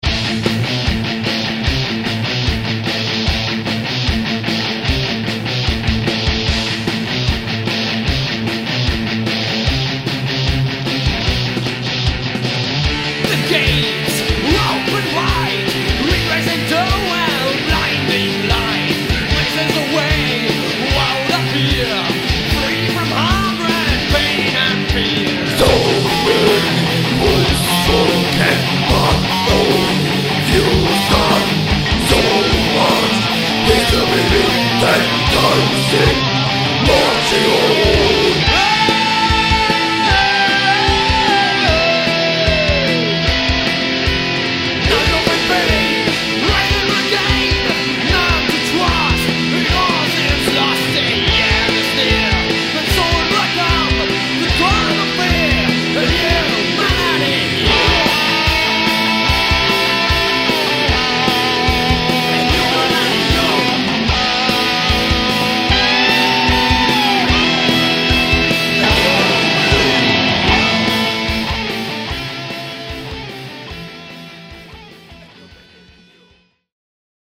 Millennium Proberaum Demo